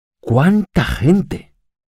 LA ENTONACIÓN EXCLAMATIVA
En general, las oraciones exclamativas tienen entonación descendente desde la última sílaba acentuada.